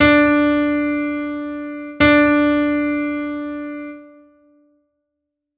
Audio nota RE